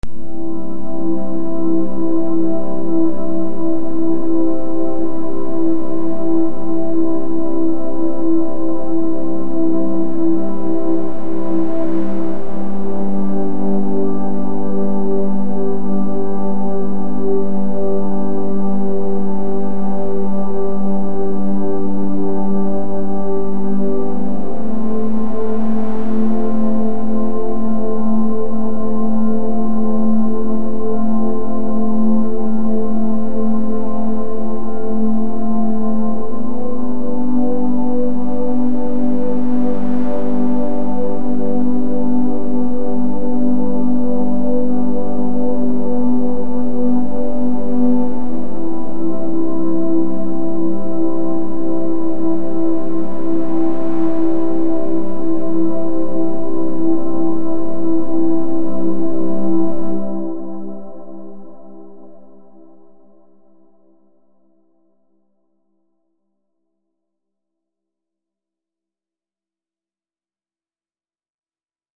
Theta Relaxation (Binaural Beats 6Hz)
This 60 minute track was created using 6Hz binaural beats. Designed to be listened to with headphones, these tones can help you relax, meditate, and improve your sleep.
6hz-binaural-beats-clip.mp3